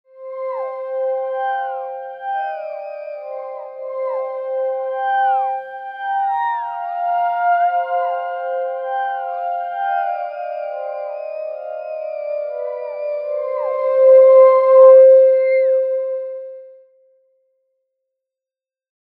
Fale Martenota
Można na nich grać jedną linię melodyczną.
Futurystyczne brzmienie instrumentu często wykorzystuje się w muzyce filmowej.
Dźwięki instrumentów są brzmieniem orientacyjnym, wygenerowanym w programach:
Fale-Martenota.mp3